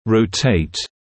[rəu’teɪt] [роу’тэйт] вращать, вращаться, разворачиваться, ротировать